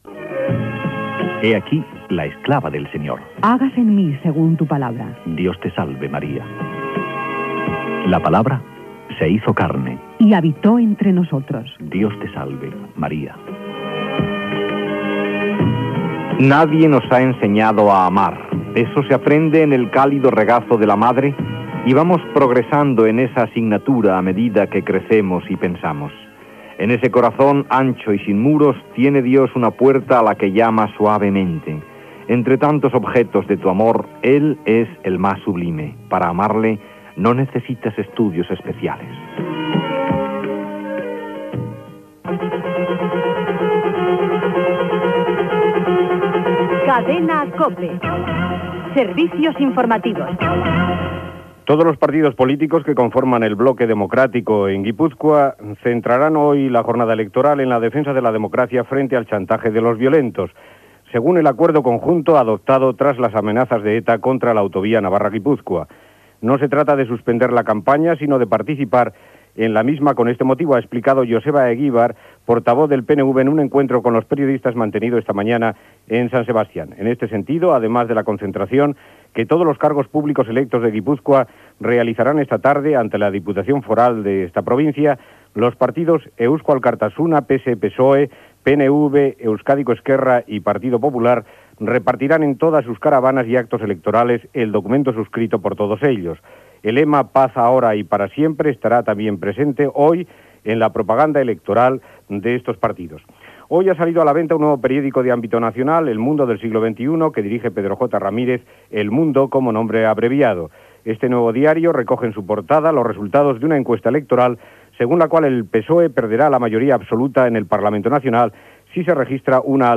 Ràdio Sabadell EAJ-20
A les 12.00 pregària de l'Angelus. Careta del butlletí de notícies: campanya "Paz ahora y para siempre" a Euskadi, surt al mercat el diari "El mundo" , entrevistes de TVE als candidats a les eleccions. Publicitat.
Informatiu